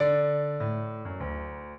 minuet15-8.wav